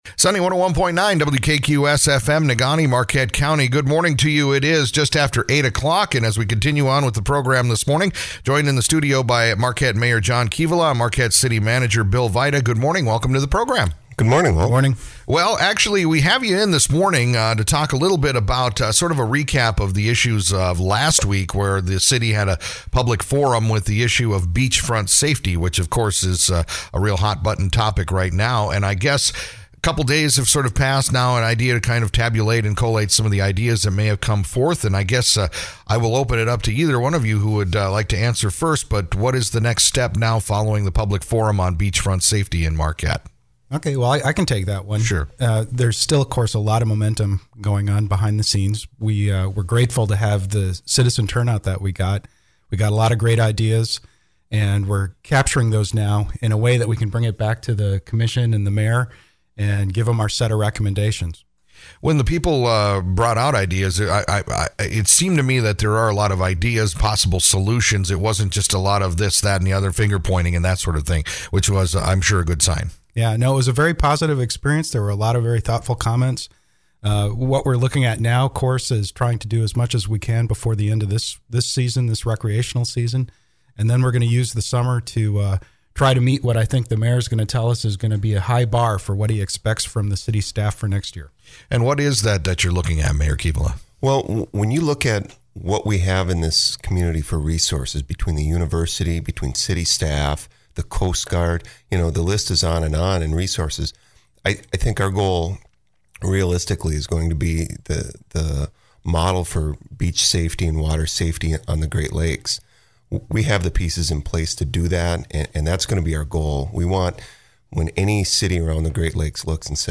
Marquette Mayor John Kivela and City Manager William Vajda joined us recently to discuss the latest in the plans to increase the safety of the beaches in the city. The action comes after the drownings that have happened this summer in the waters of Lake Superior right off of some of Marquette’s most popular beaches.